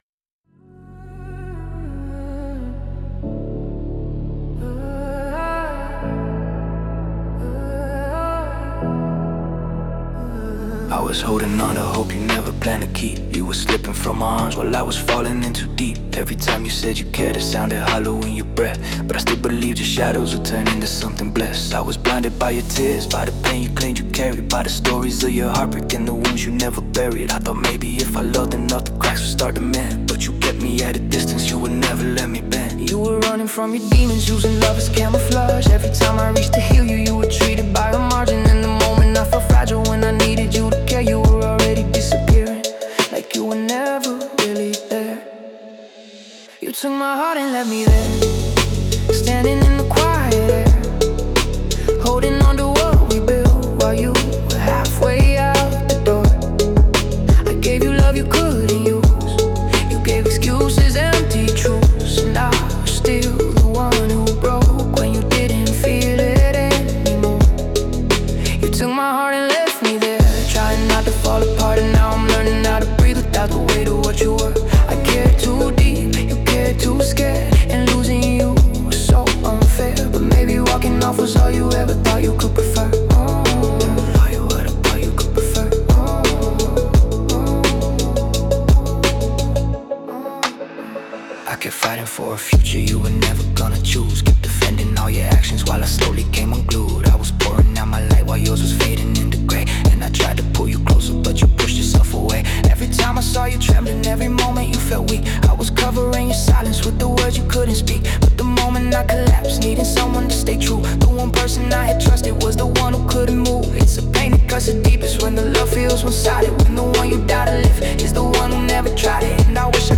Royalty-Free Emotional music track
Genre: Emotional Mood: heartbroken